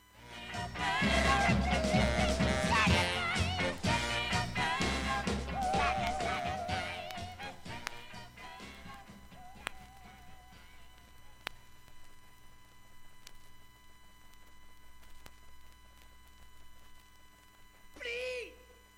ブットイ音質良好全曲試聴済み。
A-1終りフェイドアウト部に
わずかなプツが5回出ます。
ほかかすかな単発のプツが6箇所
◆ＵＳＡ盤オリジナルLP, Album, Mono